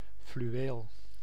Ääntäminen
Ääntäminen France: IPA: [və.luʁ] Tuntematon aksentti: IPA: /z/ IPA: /t/ Haettu sana löytyi näillä lähdekielillä: ranska Käännös Ääninäyte Substantiivit 1. fluweel 2. velours Suku: m .